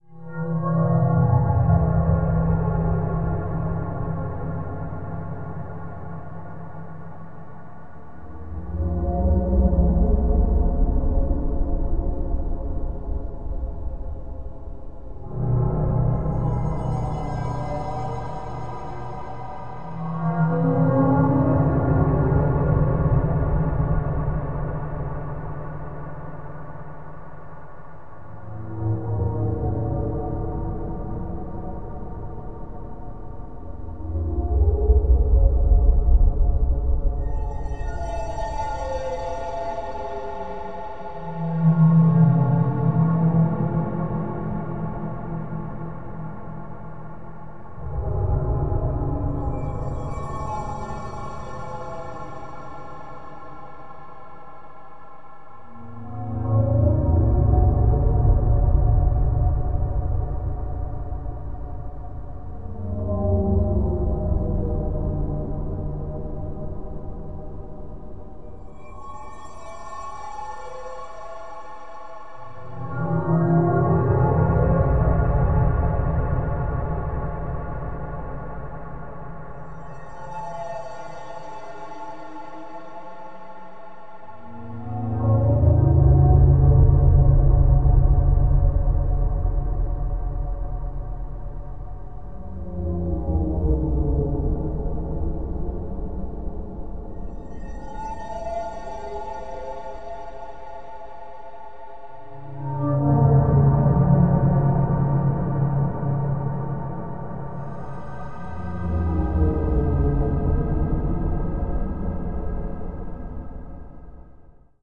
EerieAmbienceLargeSca_MV005.wav